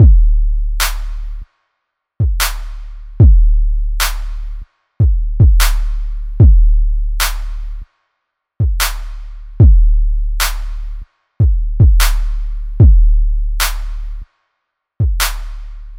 01 Drums.mp3